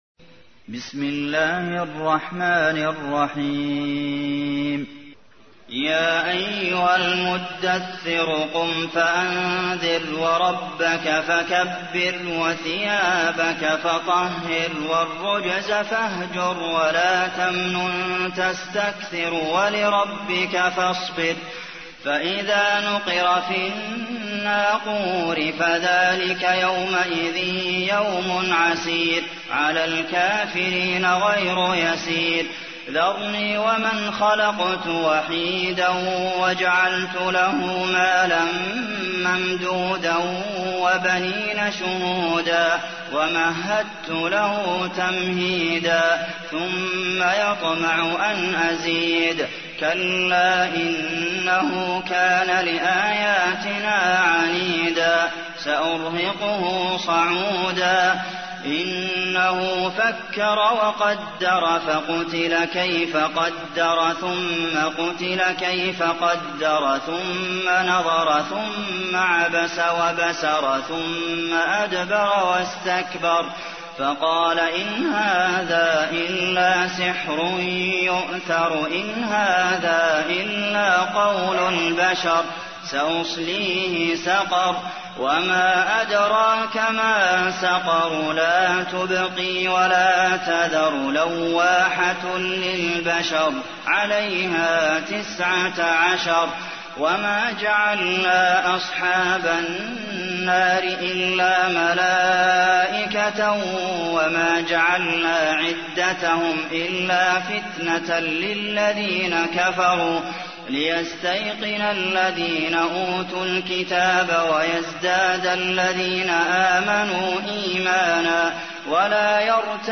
تحميل : 74. سورة المدثر / القارئ عبد المحسن قاسم / القرآن الكريم / موقع يا حسين